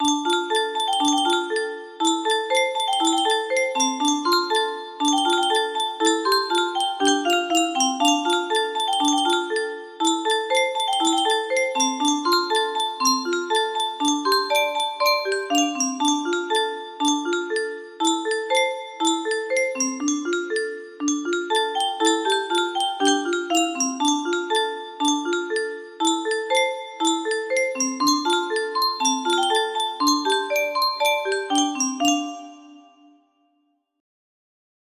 Chorus part